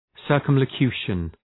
Προφορά
{,sɜ:rkəmlə’kju:ʃən}